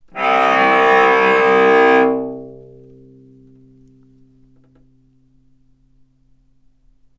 vc_sp-C2-ff.AIF